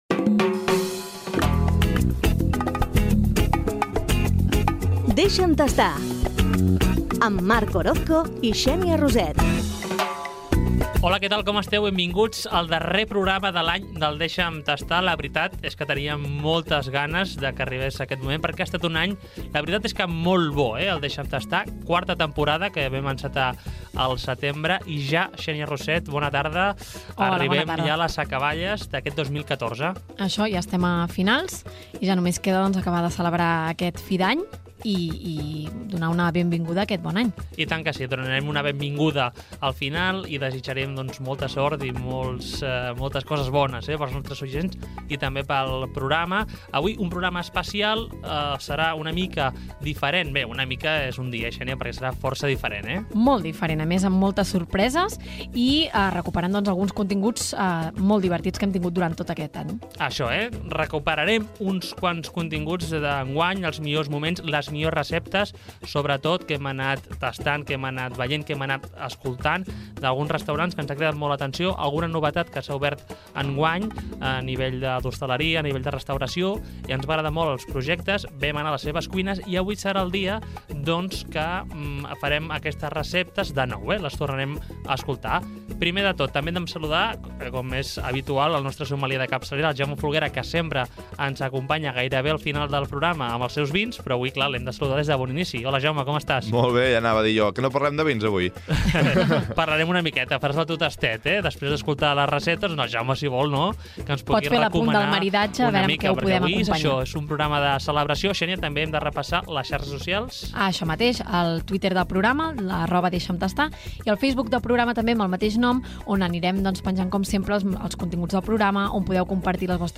Especial de cap d'any del programa. Presentació, diàleg dels presentadors i continguts
Divulgació
FM